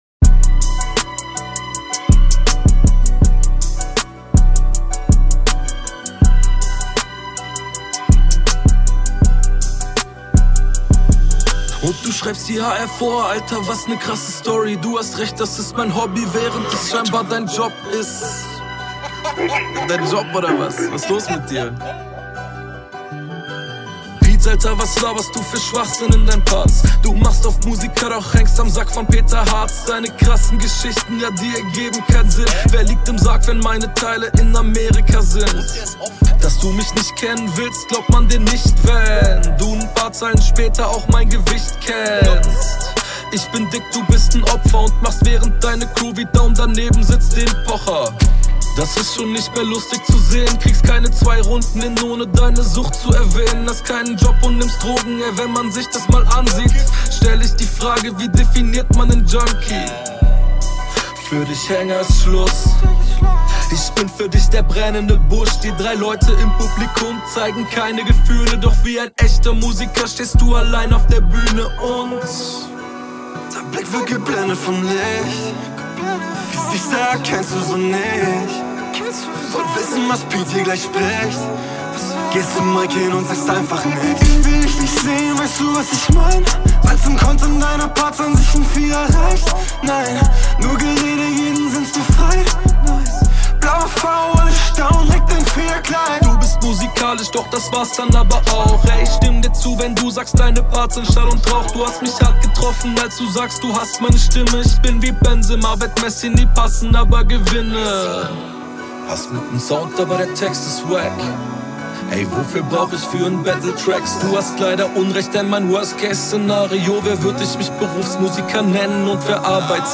Gut gerappt und Flow passt so